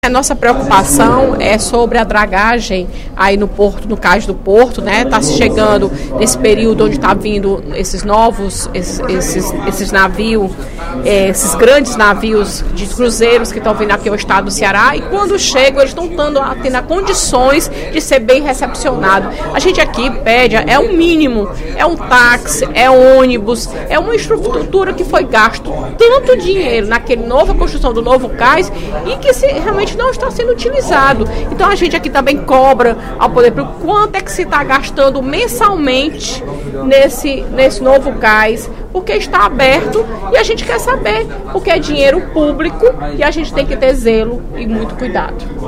A deputada Fernanda Pessoa (PR) avaliou, em pronunciamento no primeiro expediente da sessão plenária da Assembleia Legislativa desta sexta-feira (27/03), o atraso das obras do terminal de passageiros do Porto do Mucuripe. De acordo com a parlamentar, a edificação deveria ter sido inaugurada antes da Copa do Mundo.